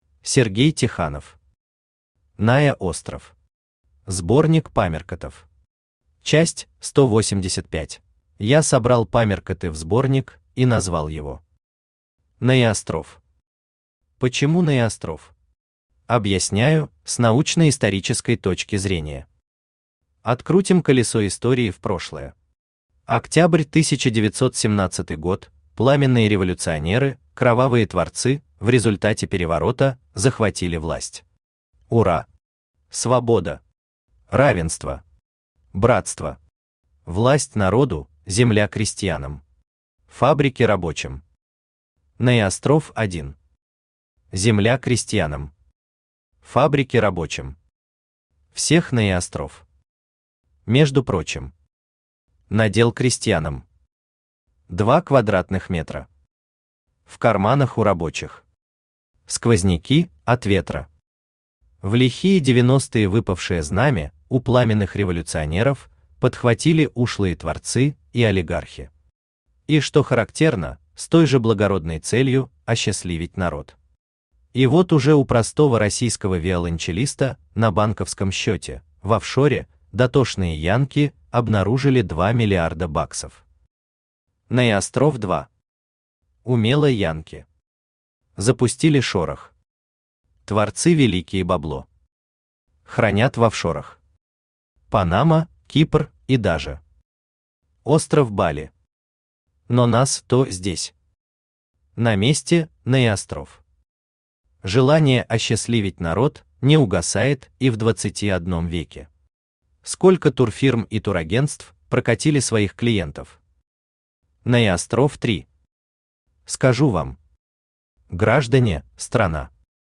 Аудиокнига НаеОстров. Сборник памяркотов. Часть 185 | Библиотека аудиокниг
Читает аудиокнигу Авточтец ЛитРес.